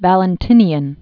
(vălən-tĭnē-ən, -tĭnyən) AD 321-375.